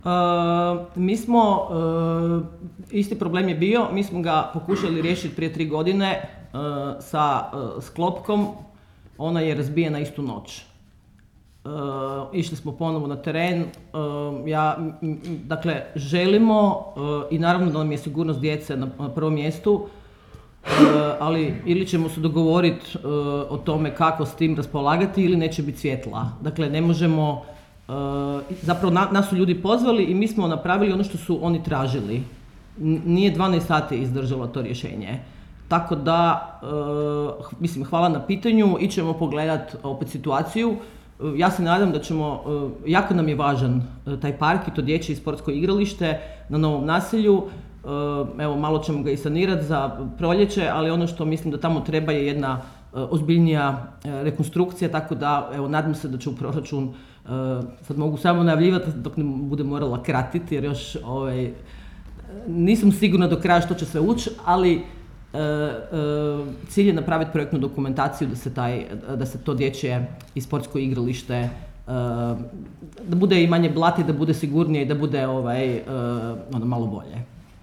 Nakon ovotjedne press konferencije gradonačelnice Suzane Jašić, osuđeni sami na sebe i naše čitatelje, krećemo s objavom odgovora na pitanja naših pratitelja.